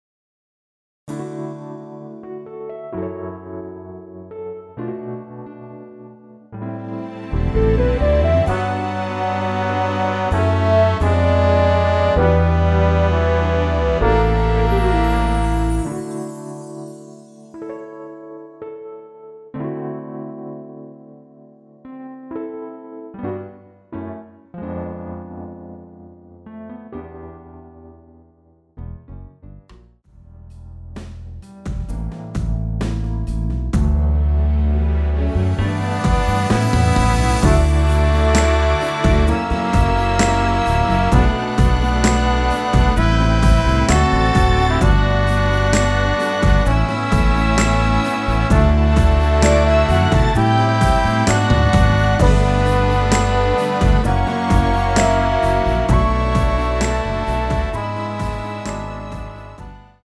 원키에서(-1)내린 MR 입니다.(미리듣기 참조)
앞부분30초, 뒷부분30초씩 편집해서 올려 드리고 있습니다.
중간에 음이 끈어지고 다시 나오는 이유는